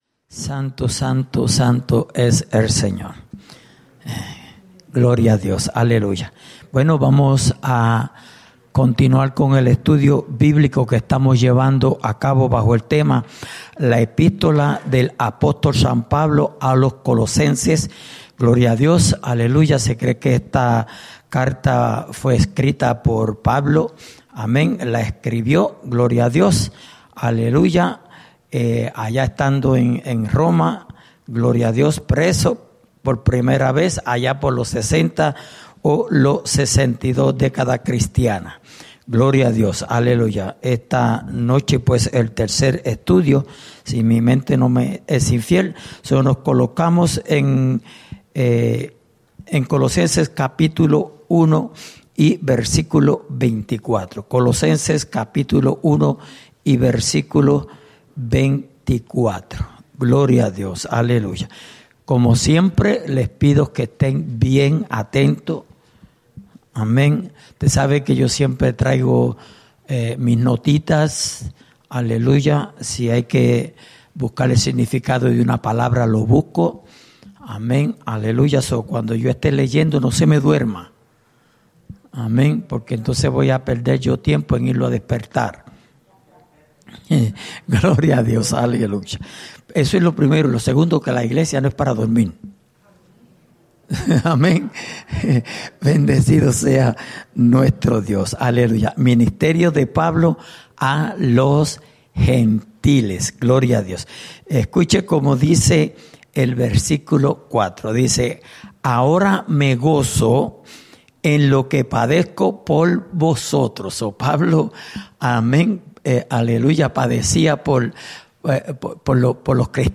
Estudio Bíblico: Libro de Colosenses (4.ª Parte)